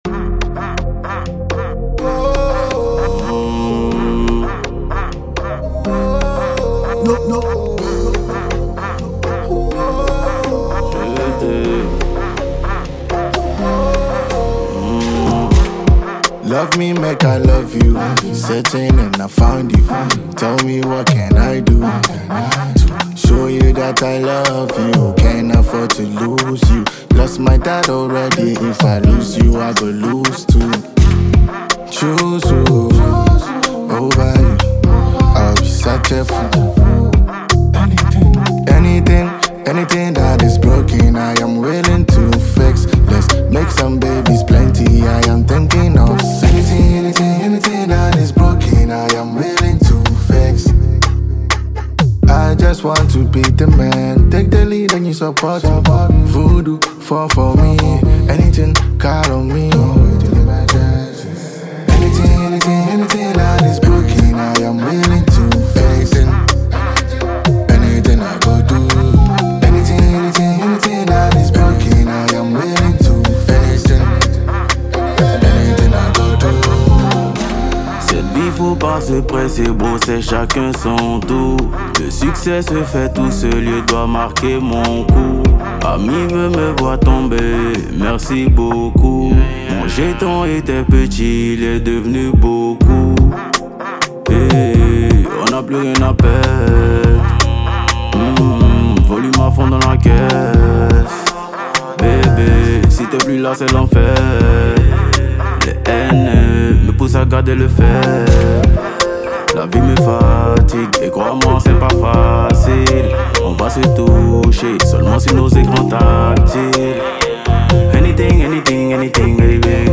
a Ghanaian trapper
Ivorian trapper